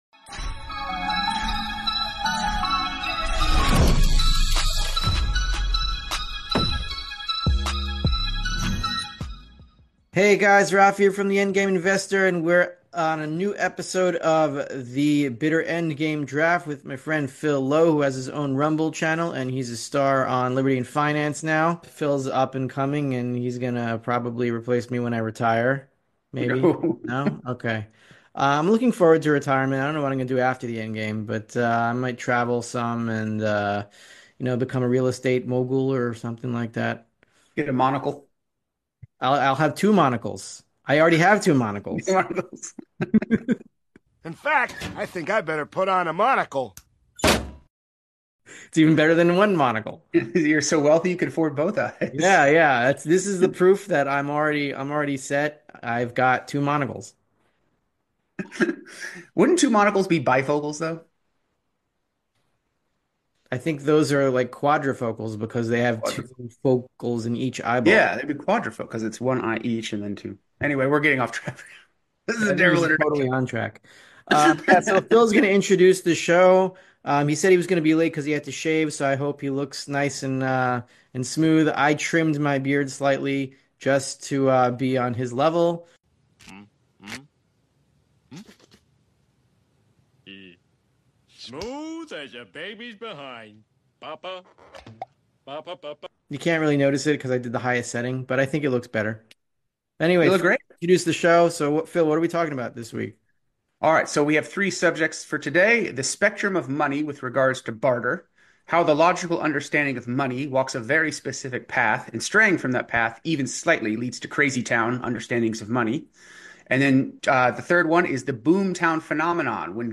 in a lively chat